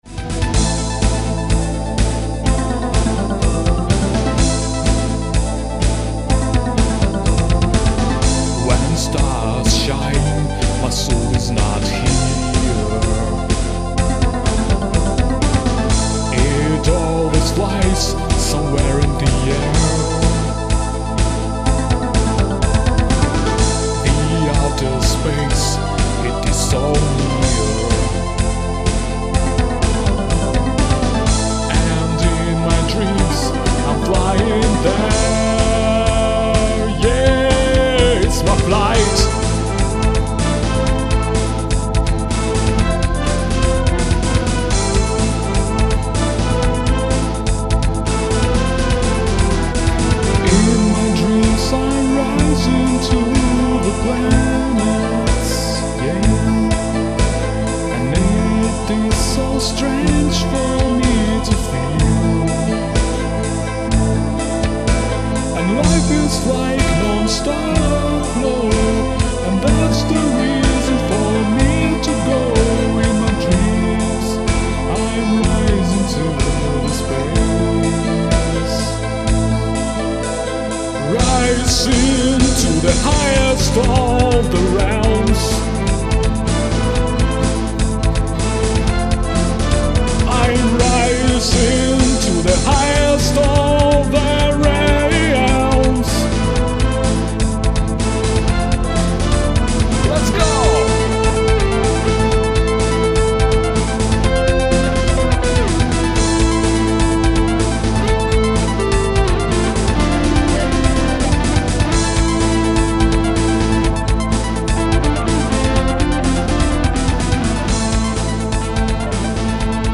Песенные композиции: